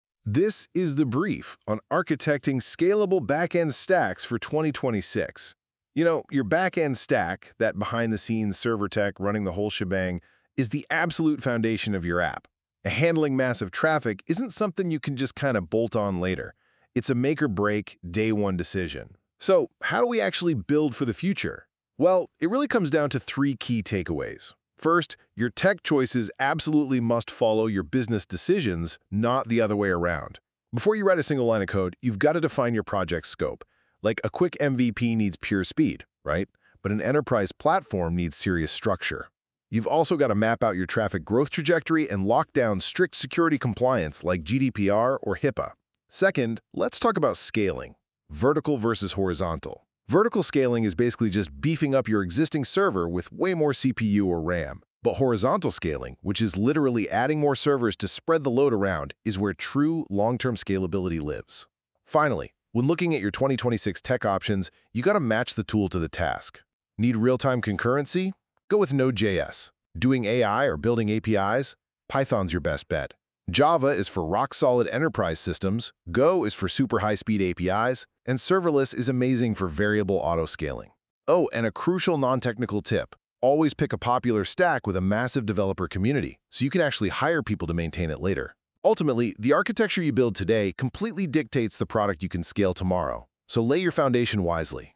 In this episode, we break down what scalability really means and when Node, Python, Go, Java, or serverless make sense. If you want to avoid rebuilding later, this conversation will help you plan smarter from the start.